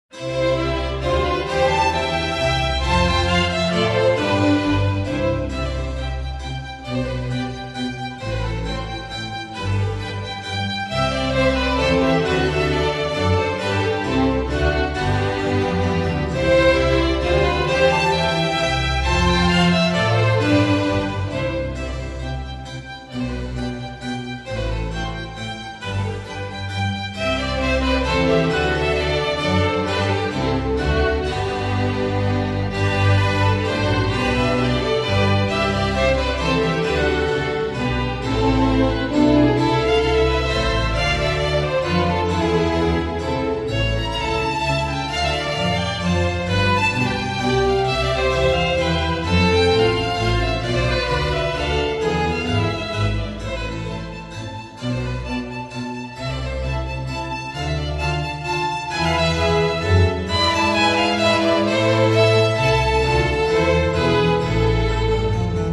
V. Vodička Sinfonia in C, 1. věta allegro 1:05 789 KB